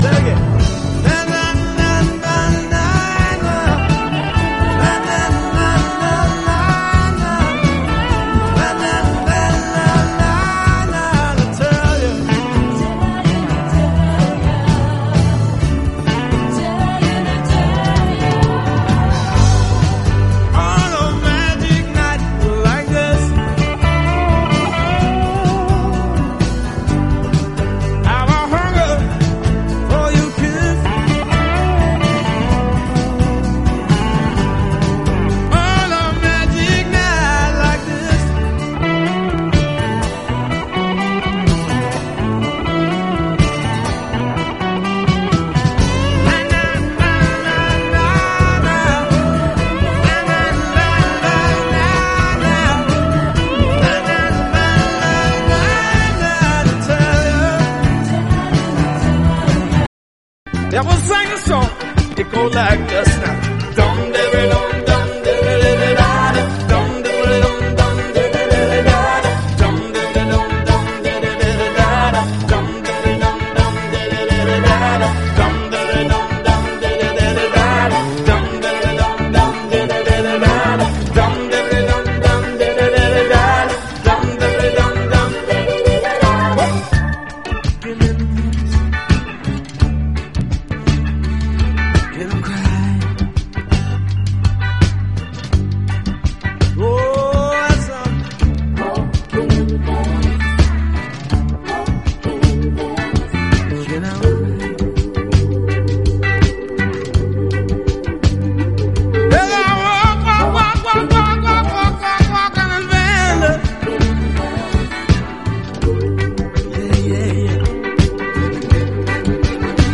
JAZZ ROCK